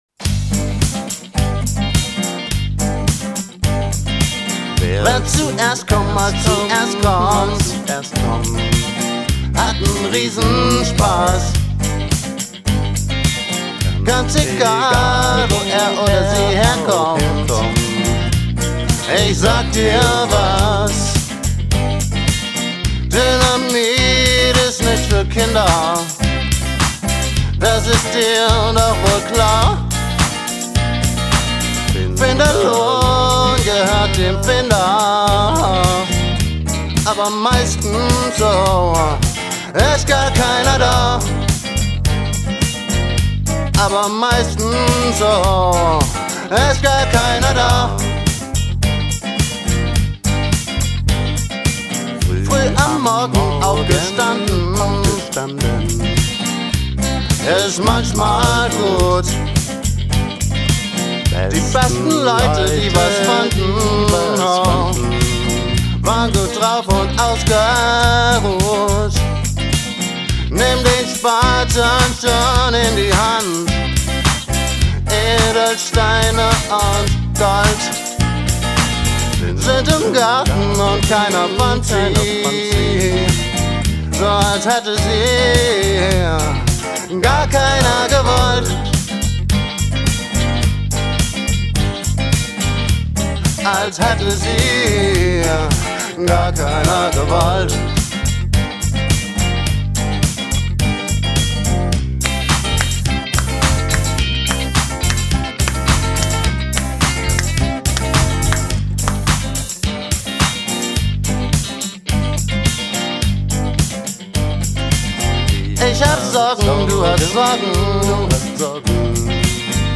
50er-Pop, d + g + p + 2voc, Song # 36, mp3